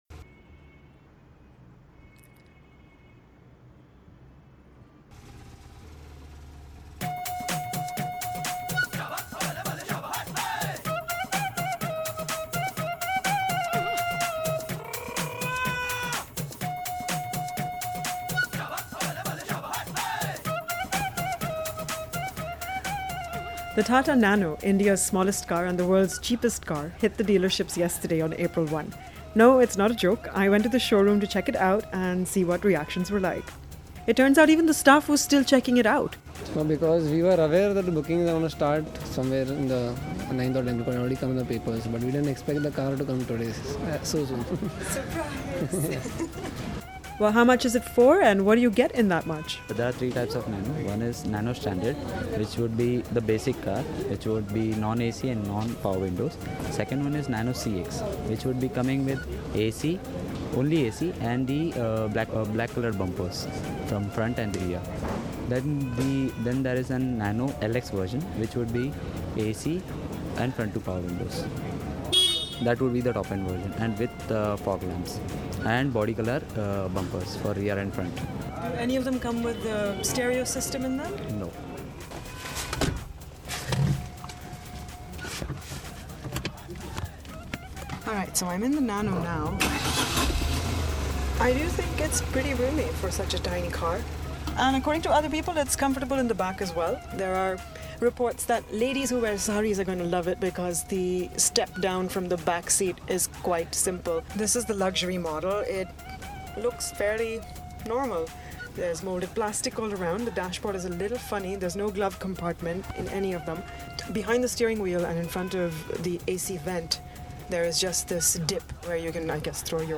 On April 1, the long awaited “people’s car” – the Tata Nano made its debut at select car dealerships in Mumbai. We went over to one in the evening and talked to (and recorded) a few of the eager people milling about.
Nicely conducted interview..pls do more of them